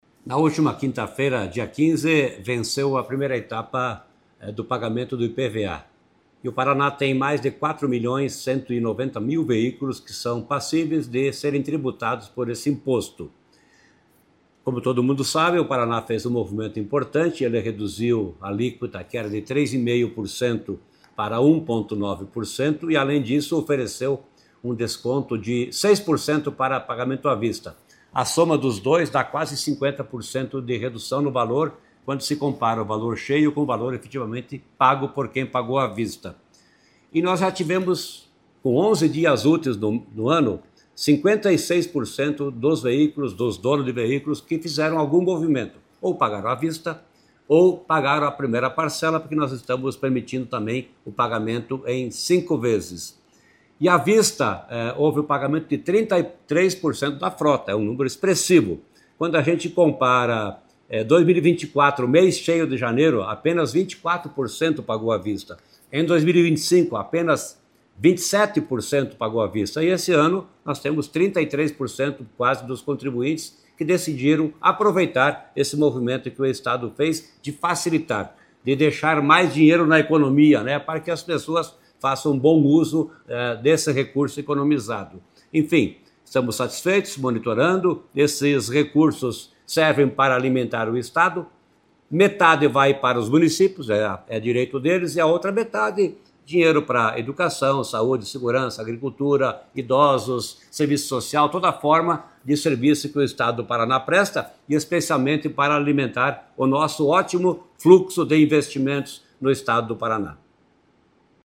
Sonora do secretário da Fazenda, Norberto Ortigara, sobre o recorde histórico de pagamentos à vista do IPVA em 2026